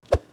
Christmas Sound Effects #1
014 throwing.wav